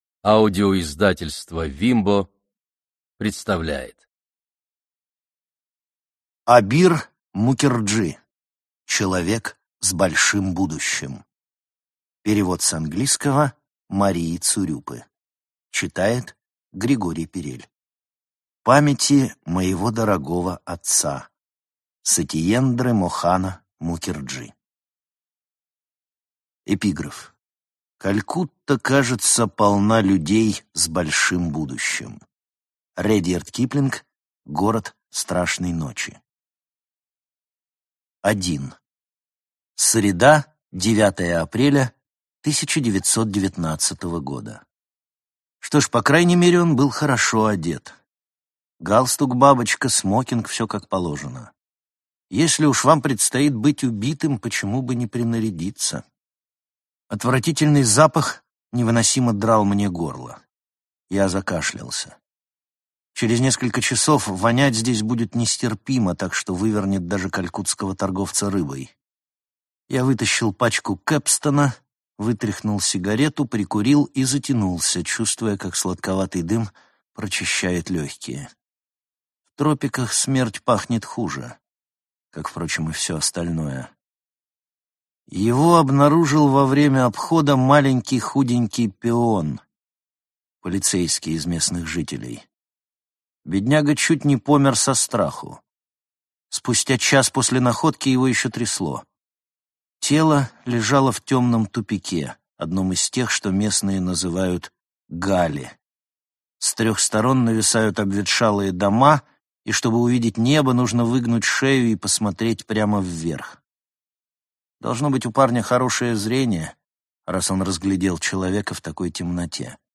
Аудиокнига Человек с большим будущим | Библиотека аудиокниг